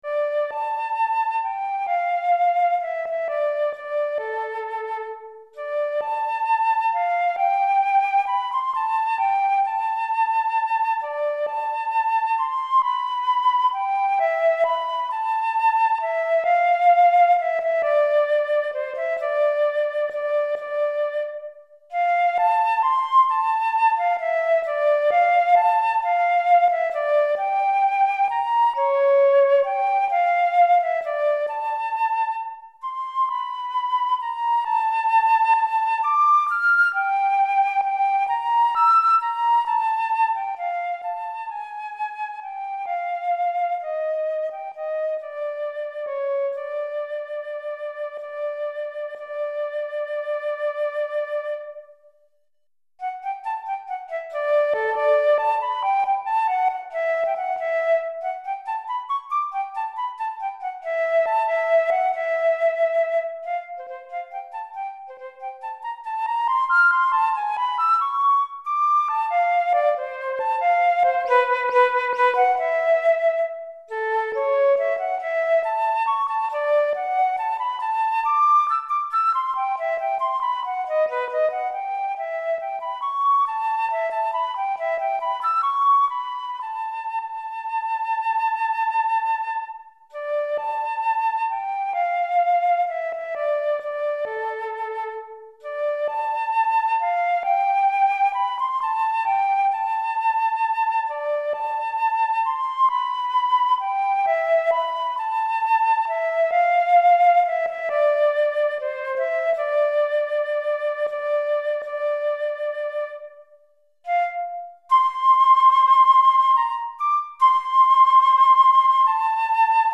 Pour flûte solo DEGRE FIN DE CYCLE 1 Durée